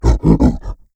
MONSTER_Exhausted_11_mono.wav